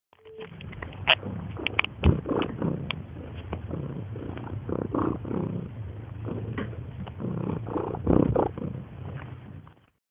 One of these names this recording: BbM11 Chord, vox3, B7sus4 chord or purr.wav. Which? purr.wav